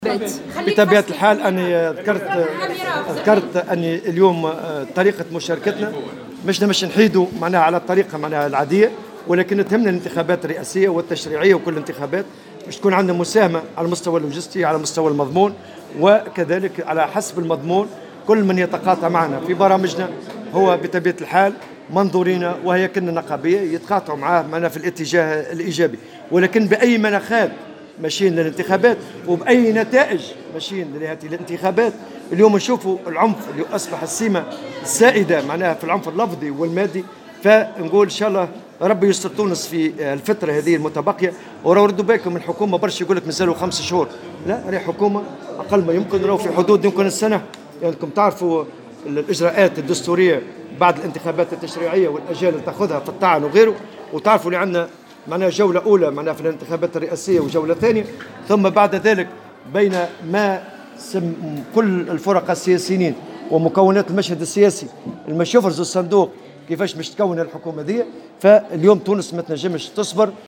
على هامش تجمع عمّالي بمناسبة اليوم العالمي للعمّال